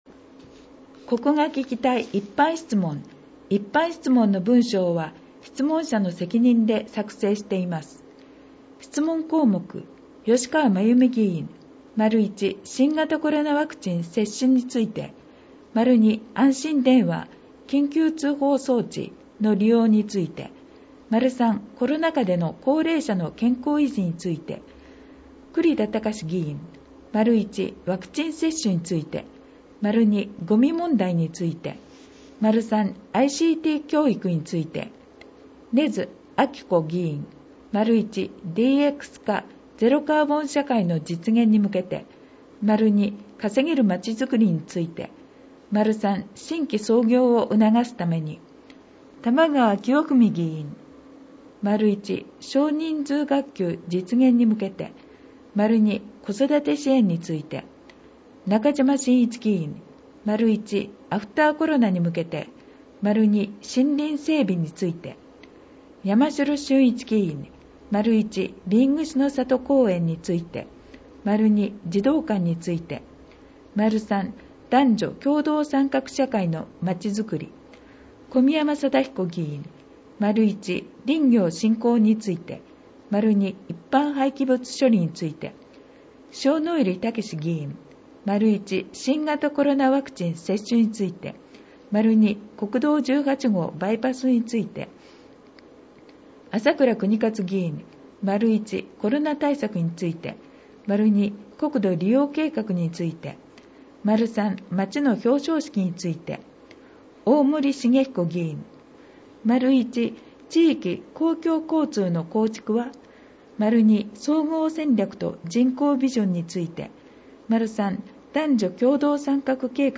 広報音訳版ダウンロード（制作：おとわの会）